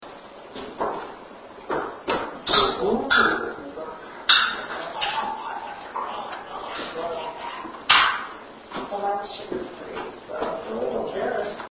Shed – 10:31 am
When prompted about facial hair, a voice is captured saying “yes”